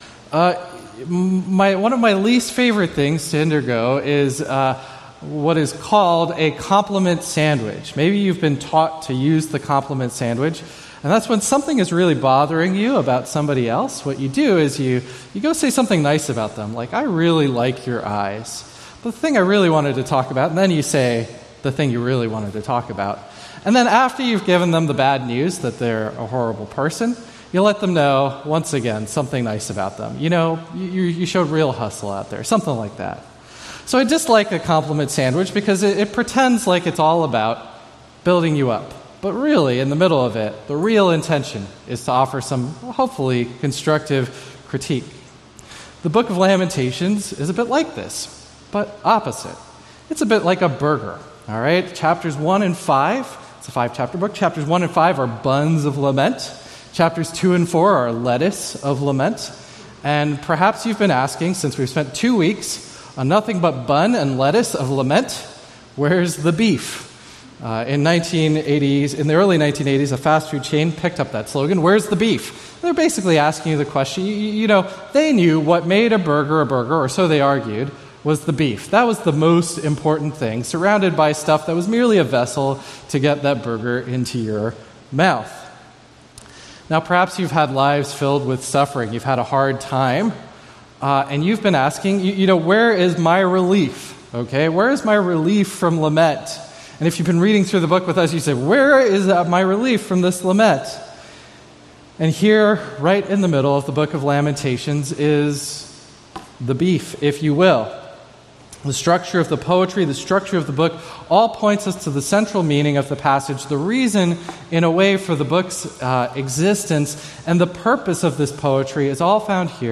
A message from the series "Lamentations ."